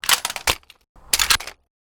sniper_reload.wav